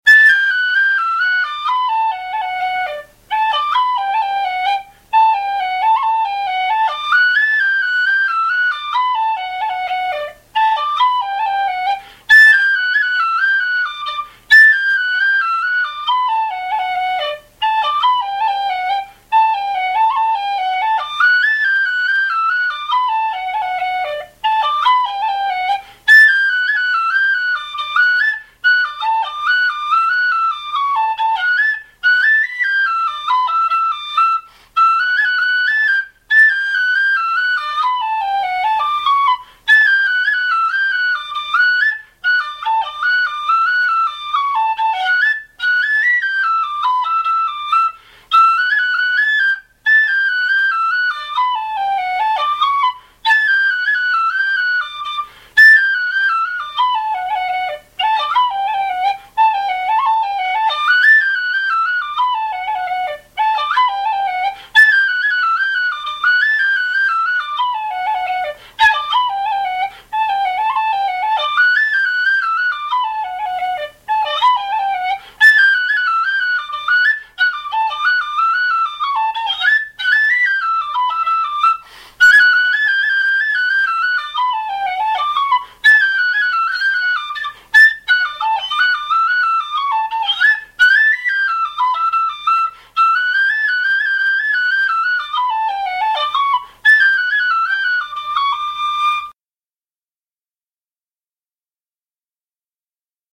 The Skylark reel D pdf 24KB txt